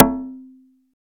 19 CONGA.wav